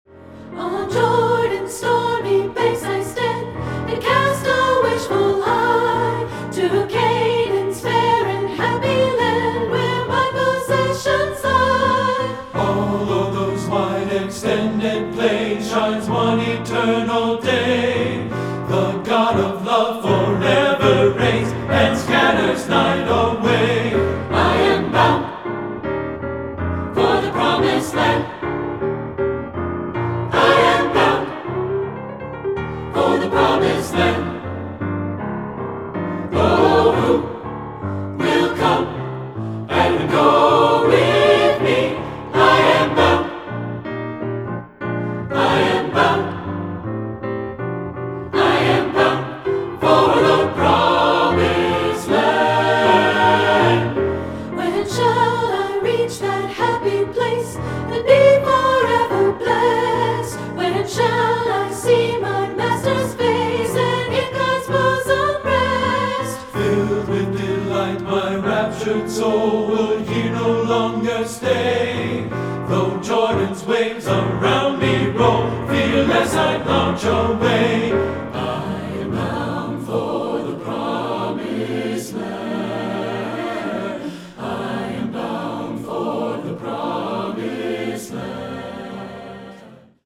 Choral Spiritual
SATB